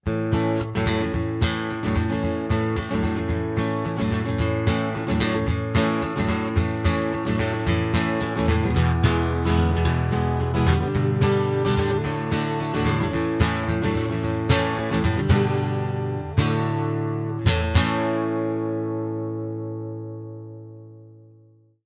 Acoustic 1
That’s the main guitar you hear at the beginning.